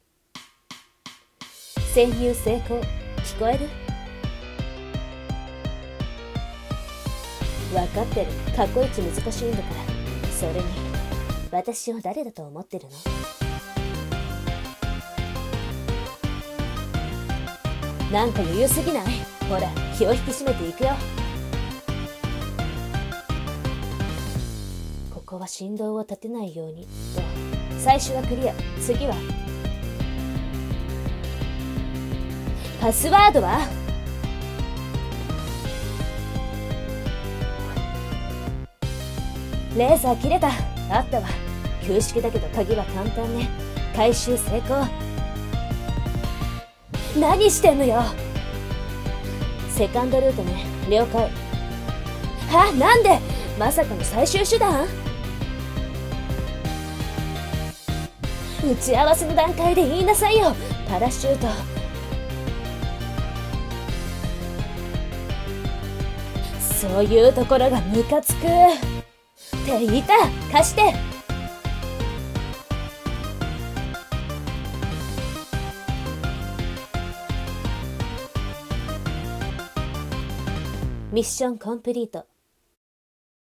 声劇】蒼い空への逃飛行